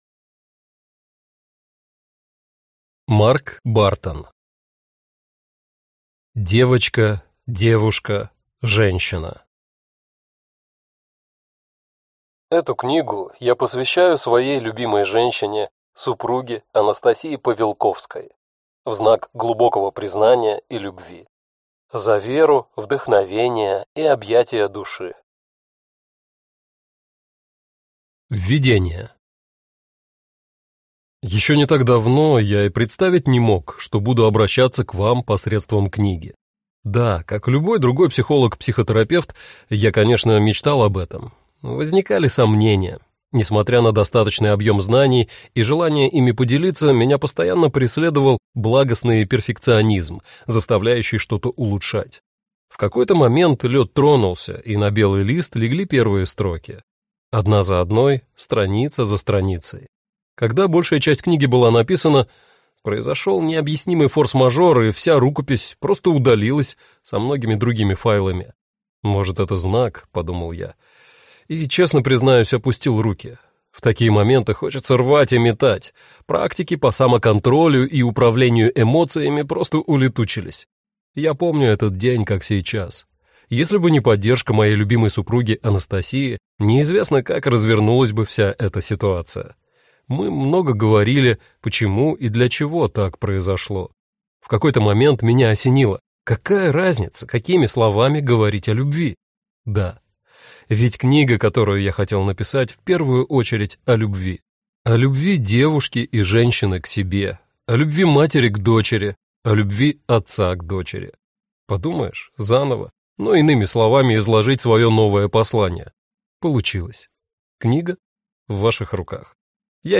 Аудиокнига Девочка. Девушка. Женщина | Библиотека аудиокниг